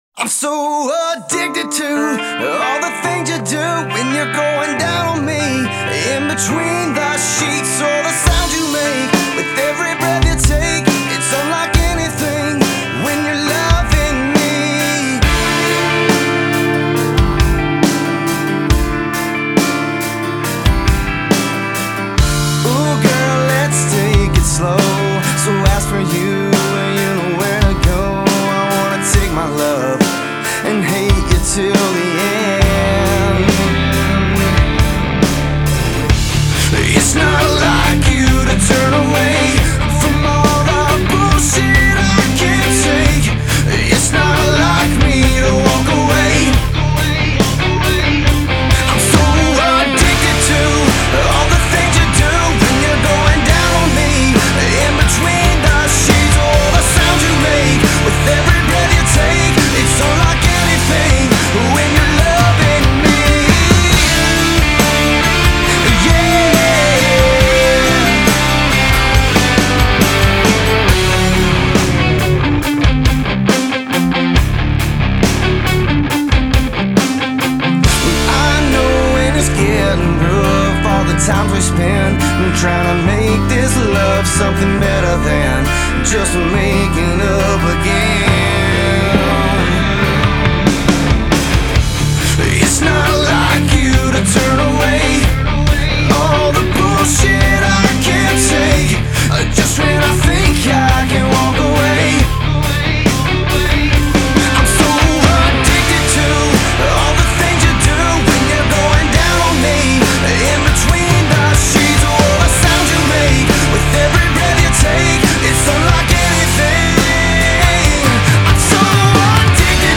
Трек размещён в разделе Зарубежная музыка / Рок.